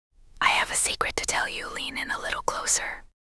04_design_whisper — voice design, whisper
영어(00_auto_en)는 꽤 자연스러운데, 한국어(01_auto_ko)는 톤이 많이 평평하고 억양이 어색한 느낌이 있어요. voice design 쪽은 female_british, male_low, whisper 모두 지정한 캐릭터가 어느 정도 구분돼 들리긴 해요.
AI, TTS
04_design_whisper.wav